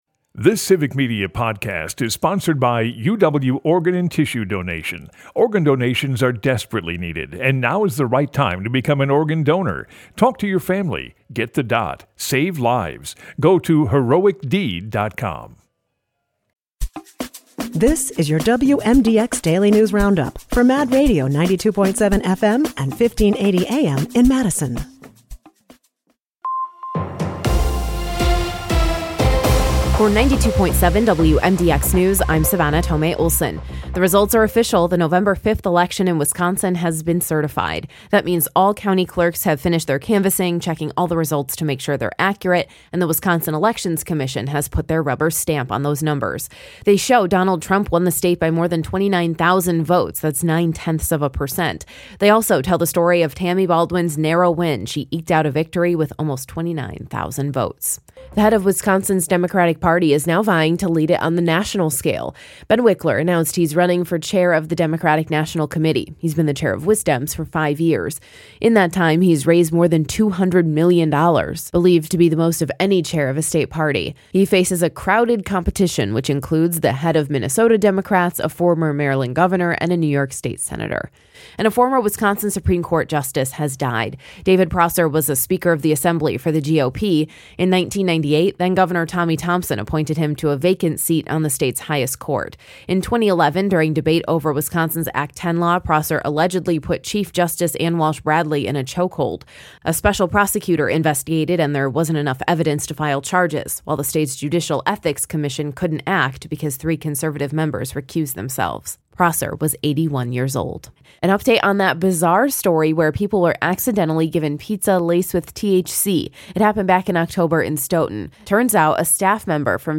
wmdx news